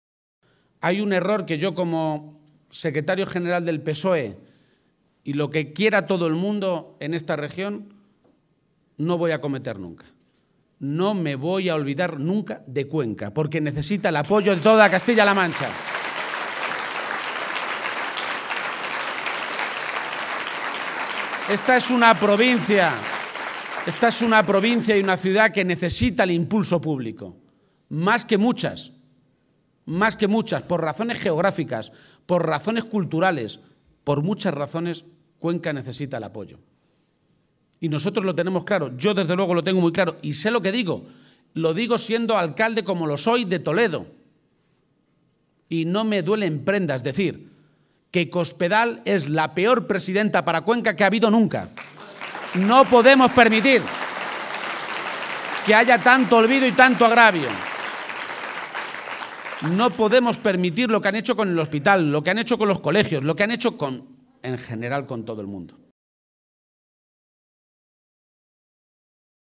El secretario general del PSOE de Castilla-La Mancha. Emiliano García-Page, ha protagonizado hoy por la tarde en Cuenca el primero de los dos mítines con los que cierra la campaña para las elecciones europeas en Castilla-La Mancha.